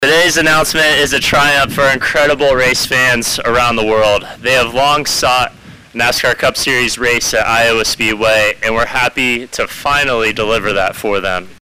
Governor Reynolds was also on hand for the announcement.